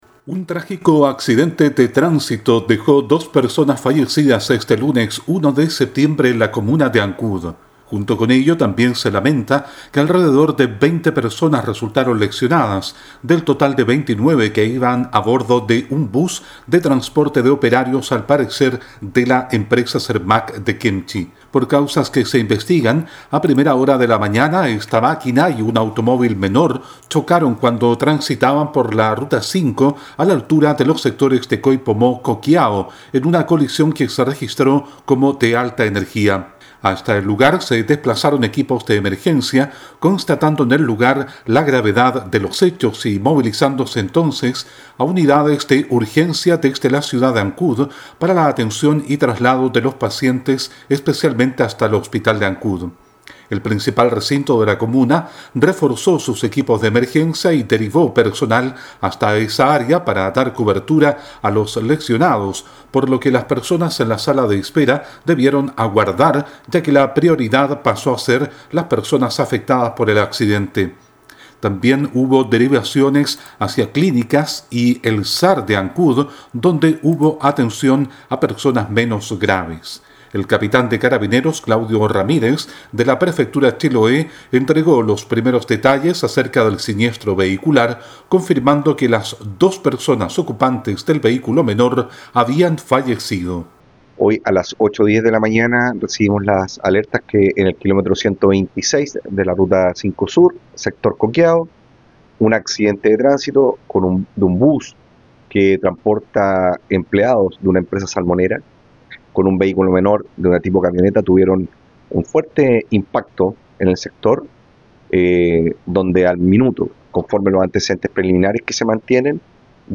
El detalle en el siguiente reporte